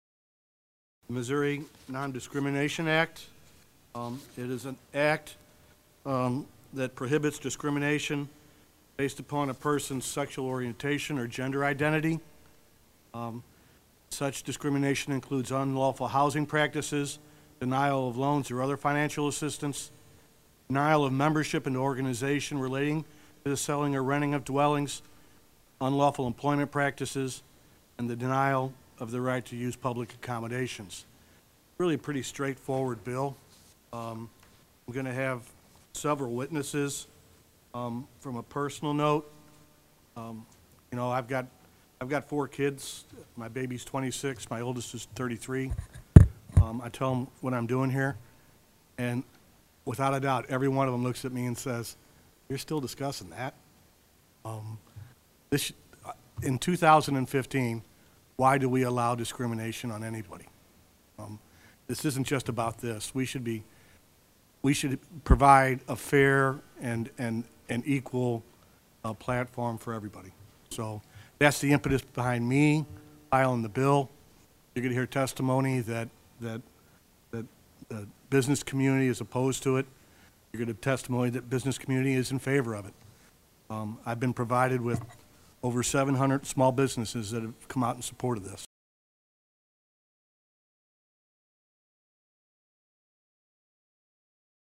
The audio and video below feature Sen. Keaveny presenting SB 237 to a Missouri Senate committee on Feb. 18, 2015.
Senator Keaveny presents Senate Bill 237 to the Missouri Senate Progress and Development Committee. His proposal would bar discrimination based on sexual orientation or gender identity.